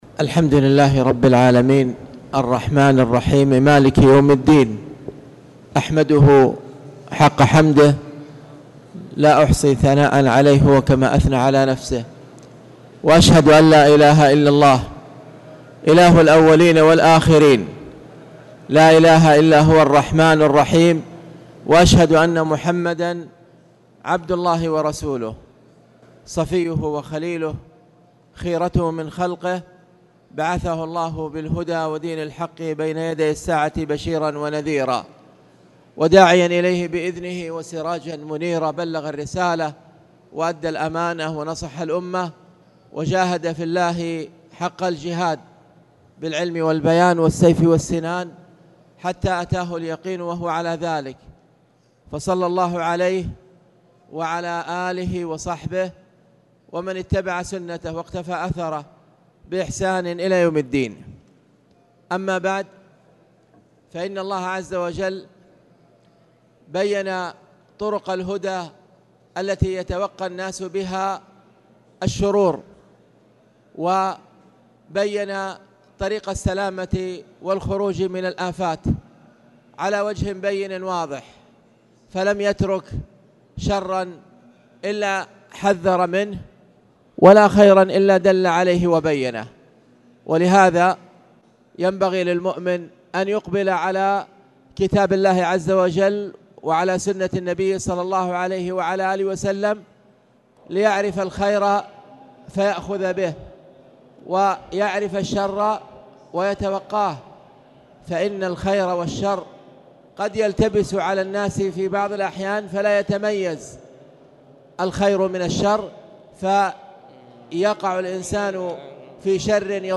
تاريخ النشر ٥ رجب ١٤٣٨ هـ المكان: المسجد الحرام الشيخ